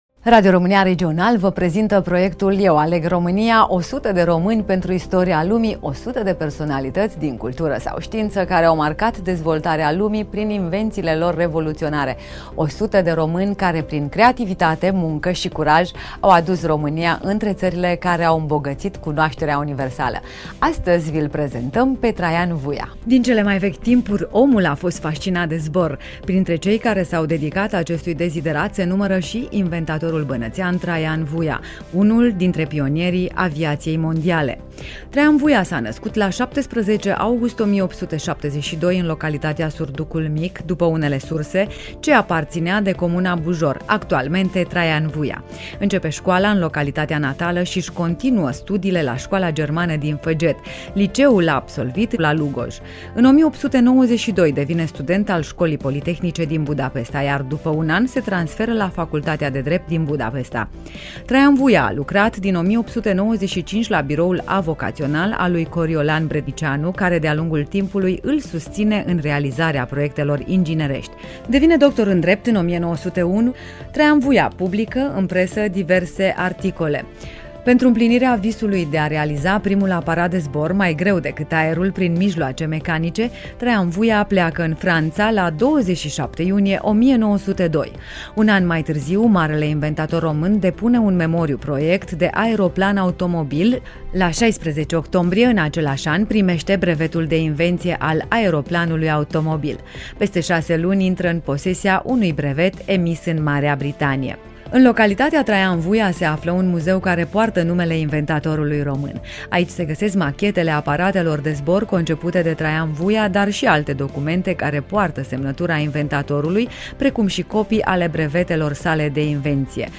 Studioul: Radio România Reşiţa